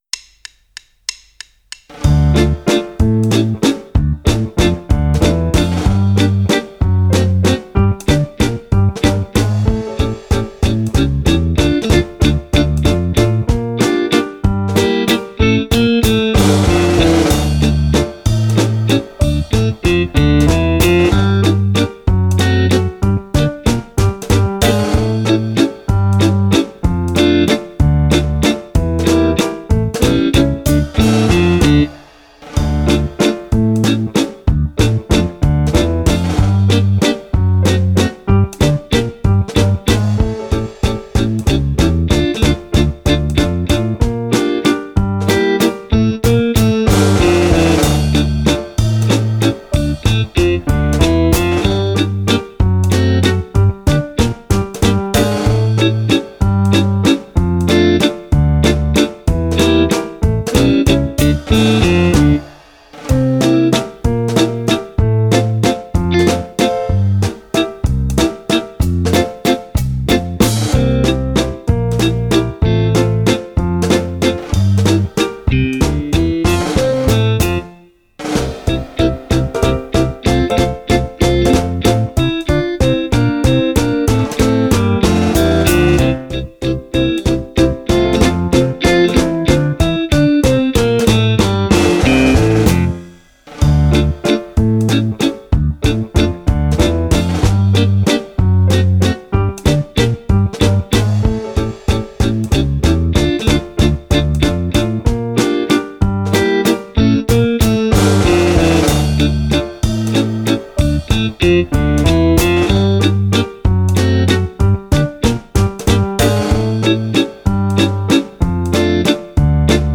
2 brani per Fisarmonica
Valzer